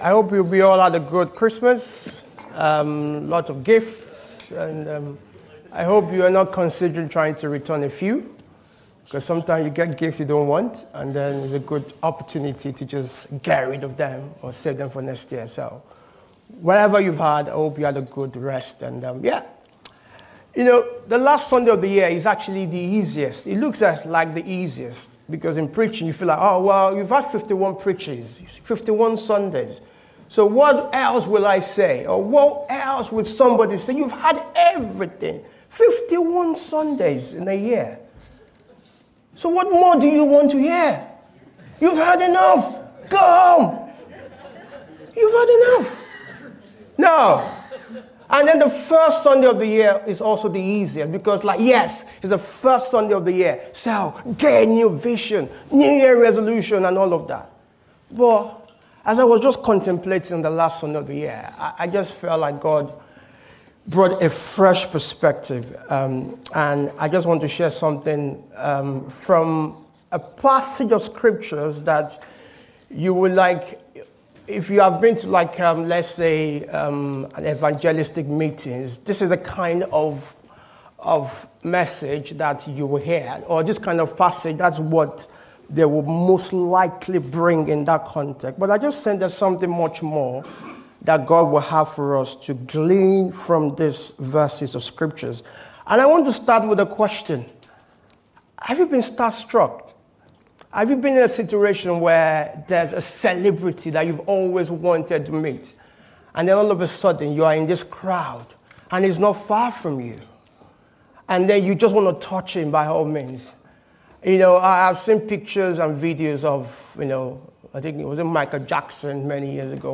This sermon calls us to step into the years ahead with a greater expectation and anticipation of God’s transformative power.